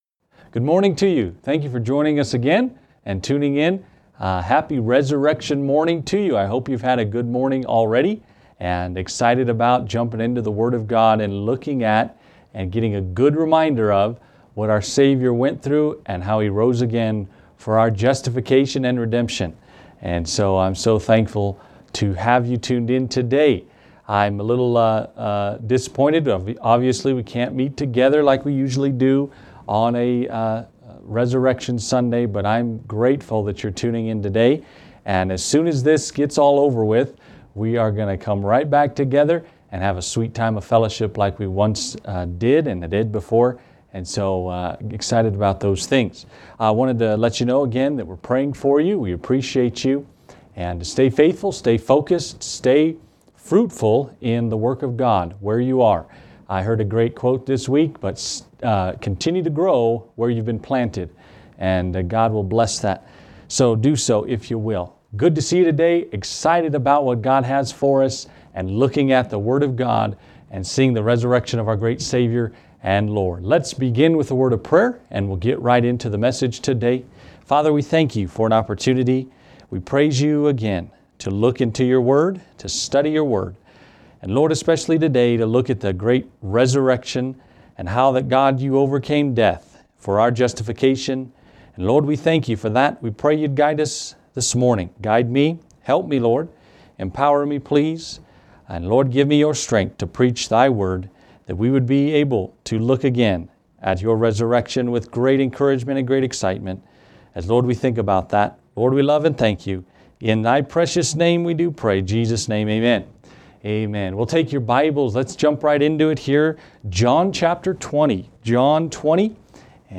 Resurrection